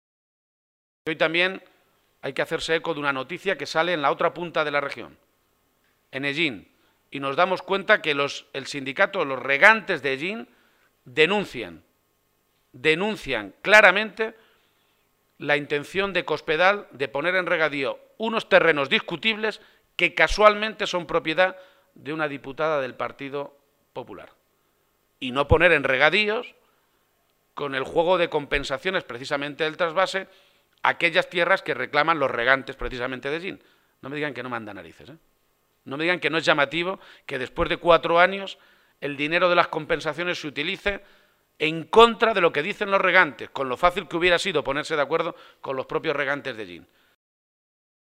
Momento de la rueda de prensa